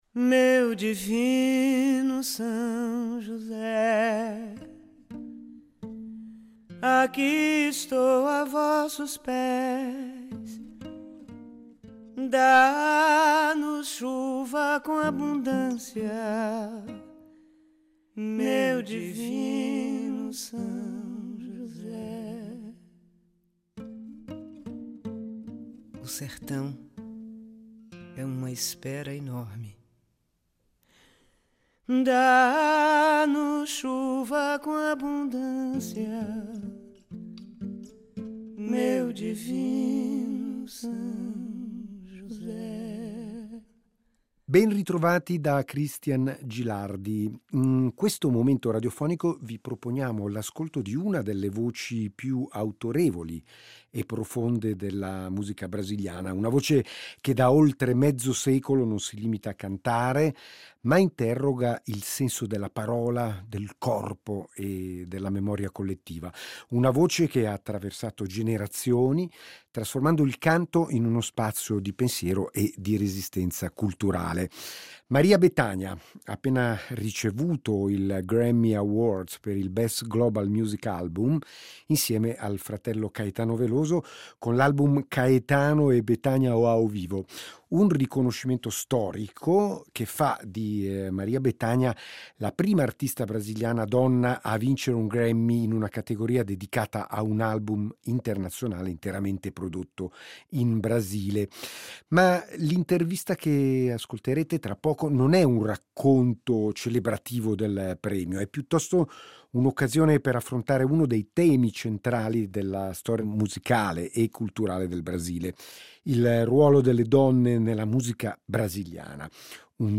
Intervista esclusiva a Maria Bethânia su Rete Due